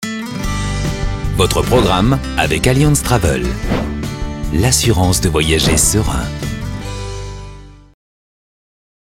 Un ton posé et chaleureux, une voix off souriante et douce dans ce Billboard TV réalisé pour Allianz Travel et diffusé sur BFMTV.